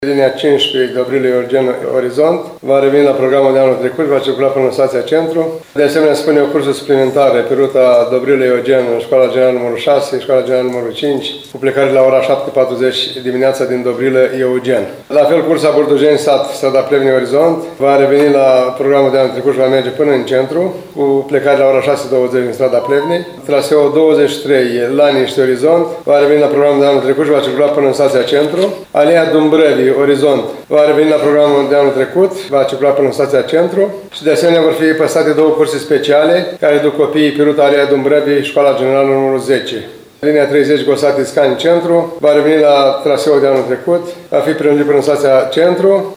Schimbările sunt vizate pe mai multe linii, după cum a detaliat astăzi primarul ION LUNGU.